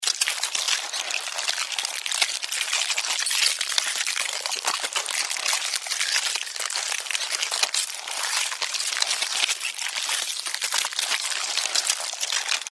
Звуки муравья
На этой странице собраны звуки муравьёв: от шороха лапок до жужжания целой колонии.